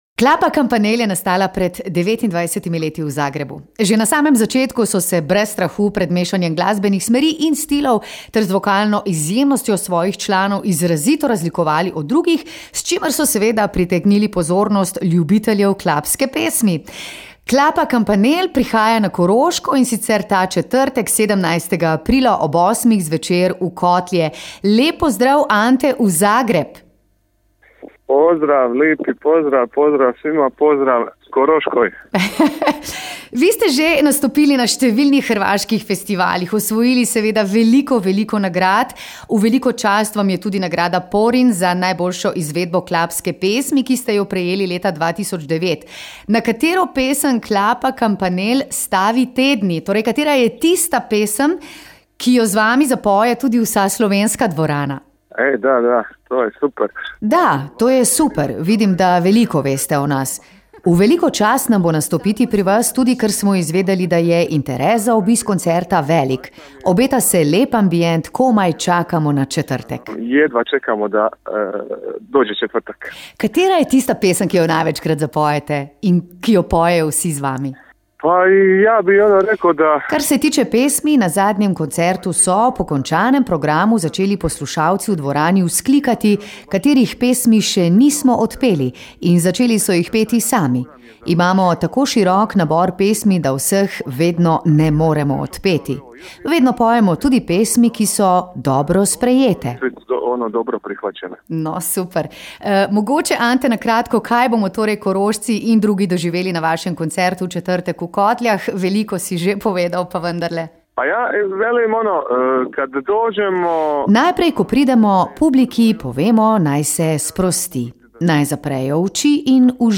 2.tenor vas povabi na koncert.